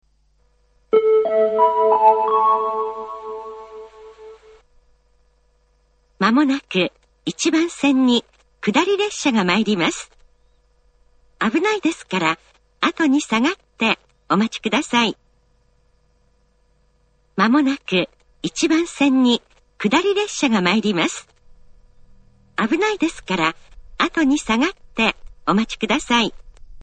１番線接近放送
tokai-1bannsenn-sekkinn1.mp3